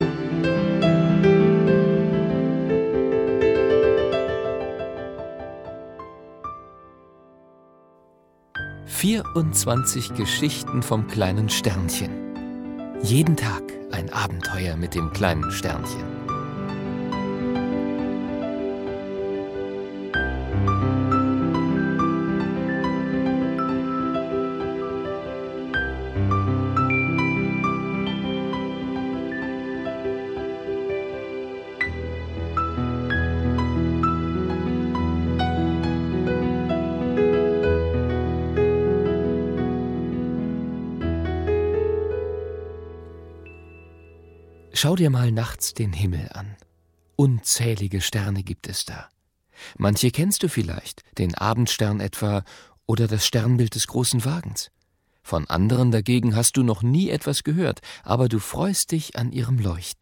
Liebevoll ausgearbeitetes Hörbuch zum gleichnamigen Kinderbuch von Cornelia Grzywa.